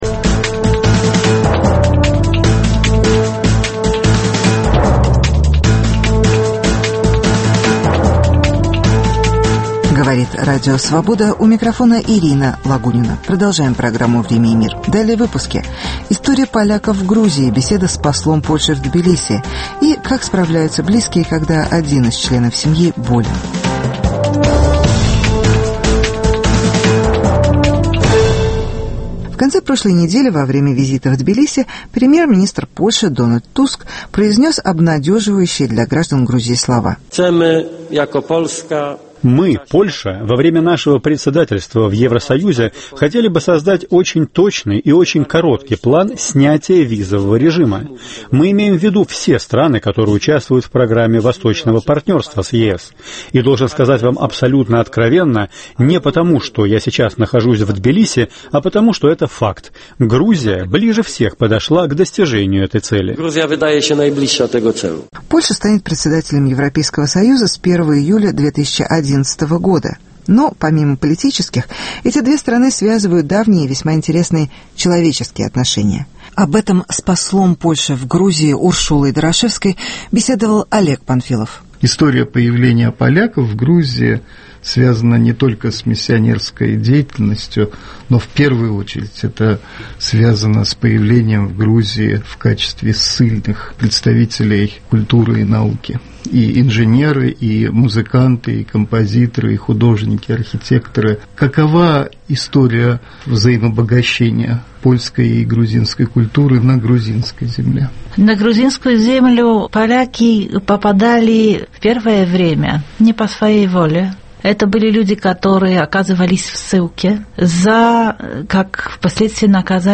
История поляков в Грузии, интервью с послом Польши.